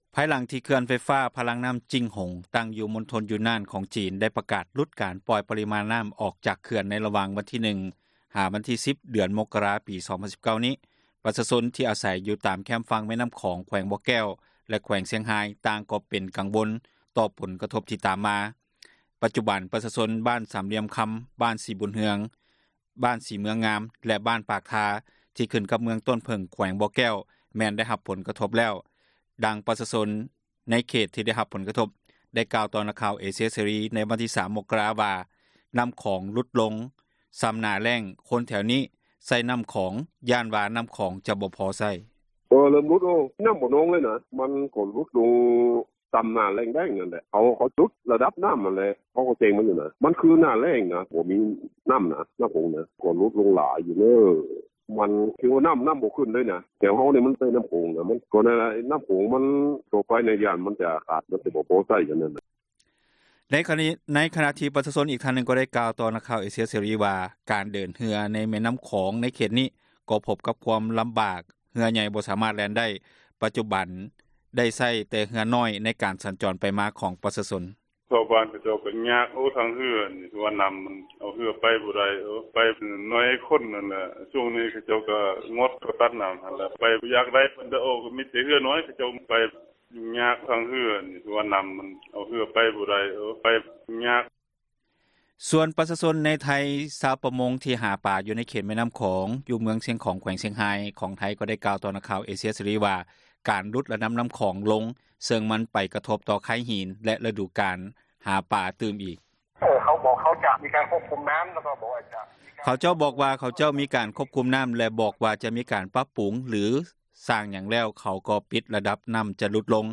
ໃນຂນະທີ່ ປະຊາຊົນອີກທ່ານນຶ່ງໄດ້ກ່າວຕໍ່ ນັກຂ່າວເອເຊັຽເສຣີວ່າ ການເດີນເຮືອໃນແມ່ນໍ້າຂອງ ໃນເຂດນີ້ ກໍພົບກັບຄວາມລຳບາກ ເຮືອ ໃຫຍ່ ບໍ່ສາມາດແລ່ນໄດ້.